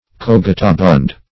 Search Result for " cogitabund" : The Collaborative International Dictionary of English v.0.48: Cogitabund \Cog"i*ta*bund`\, a. [L. cogitabundus.] Full of thought; thoughtful.